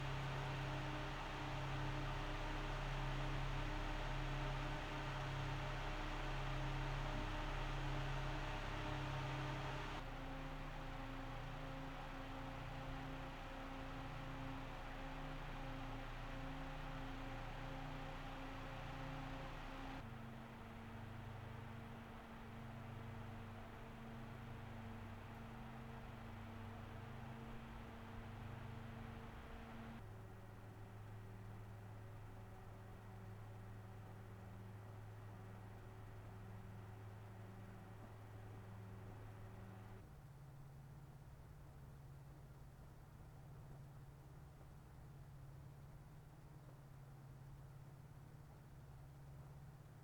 Aufnahme weiterer Kühler (anderes Verfahren, anderes Mikrofon)
be quiet! Pure Rock Pro 3: Lautstärke von 100 bis 51 Prozent PWM (31 dB)